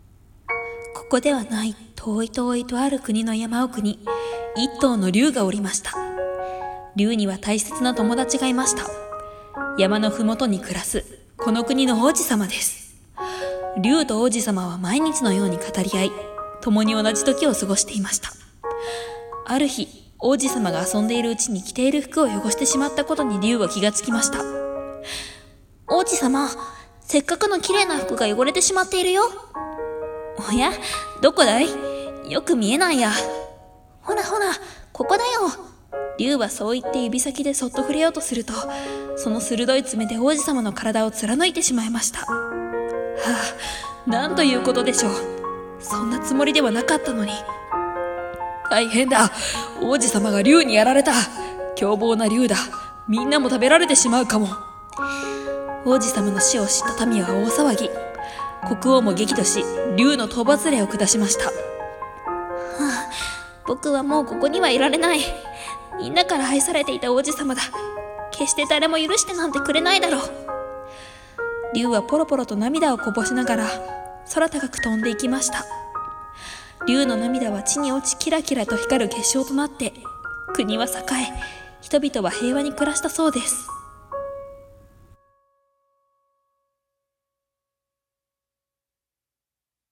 【童話声劇台本】竜の涙